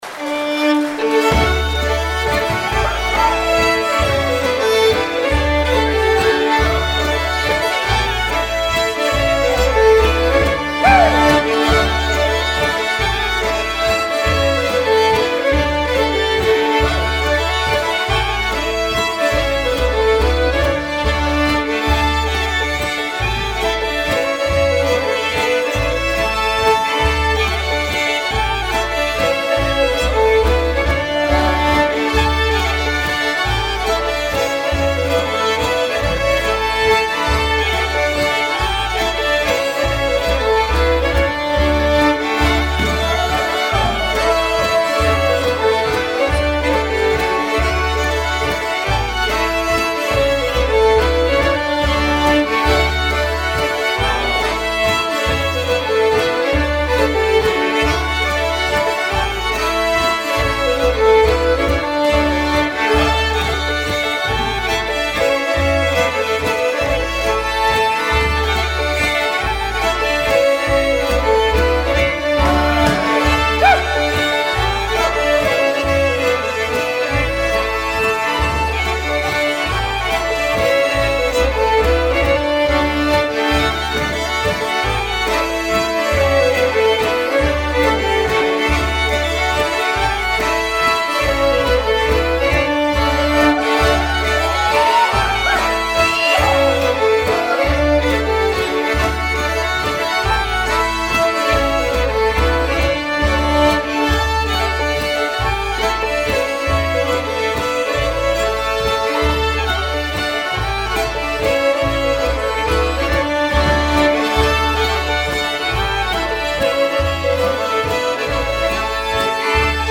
Røros pols YouTube